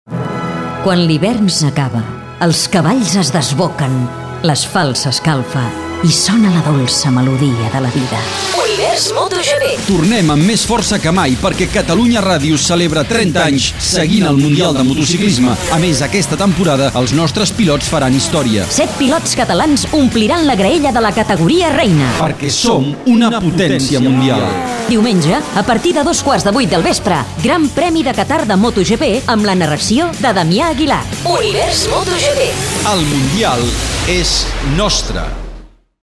PROMO Mundial Motos GP - Catalunya Ràdio, 2013